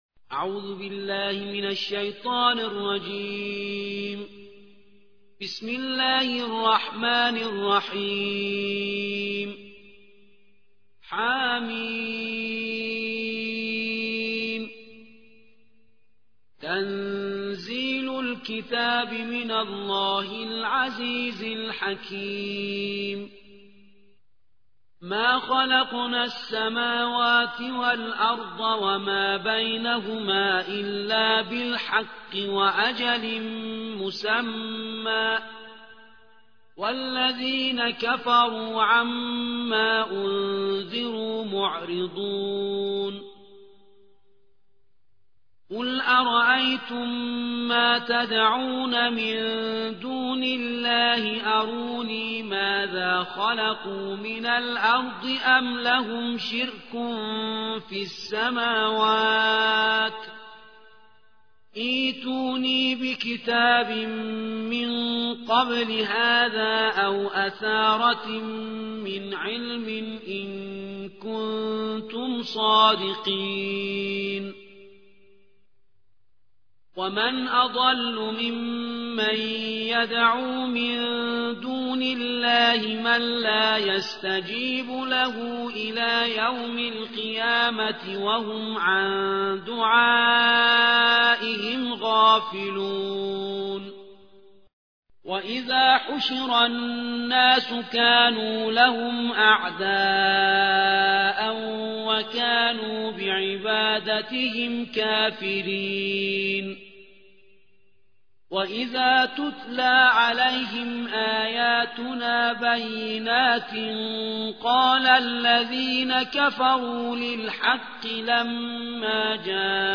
46. سورة الأحقاف / القارئ